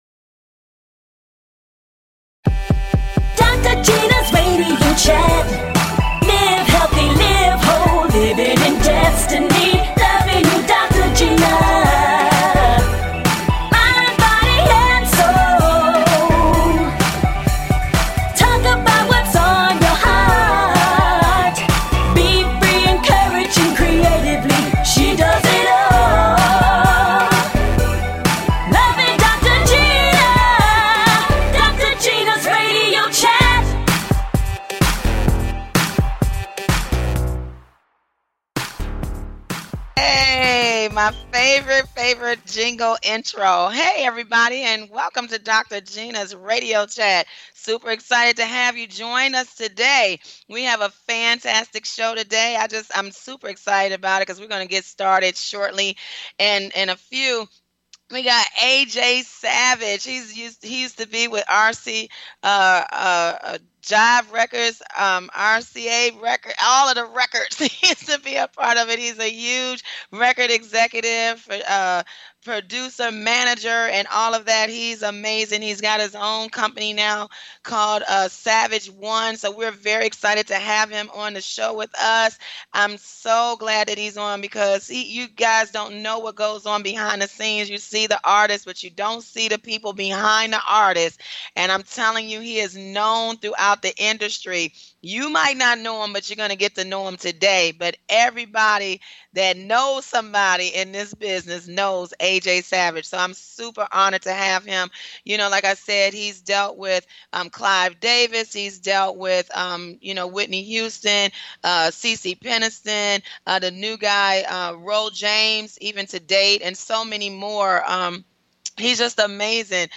Talk Show Episode, Audio Podcast
Talk Show
And full of laughter!